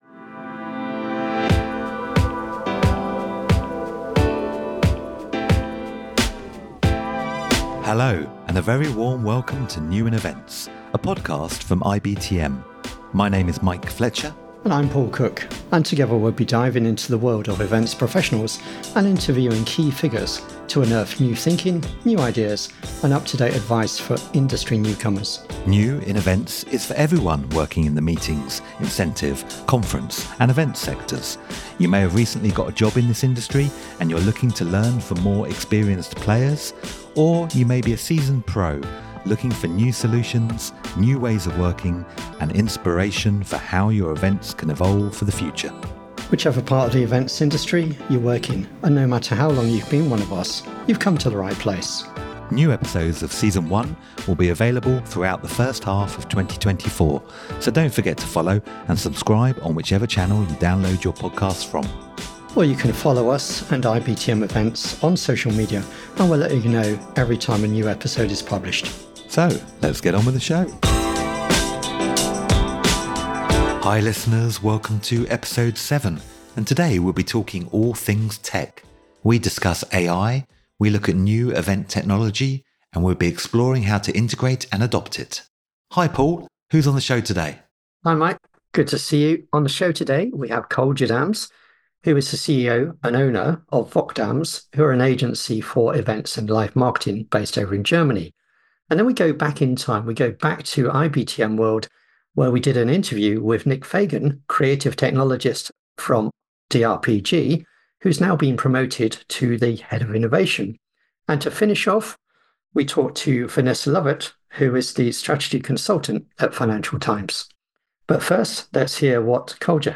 From the podcast booth at IBTM World